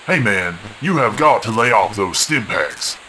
Human Male, Age 37